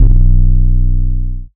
MURDA_808_SAINTS_B.wav